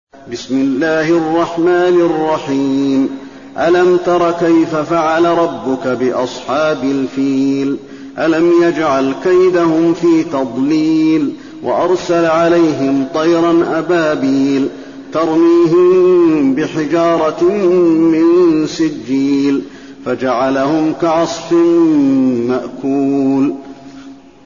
المكان: المسجد النبوي الفيل